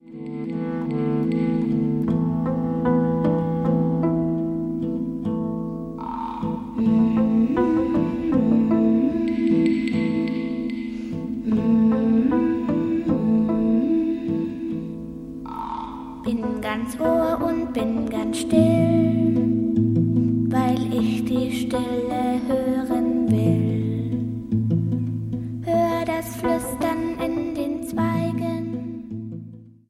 Klangbilder zum Träumen und Entspannen
lt;p>Zeitlose Klangbilder, die Sinne wecken und verzaubern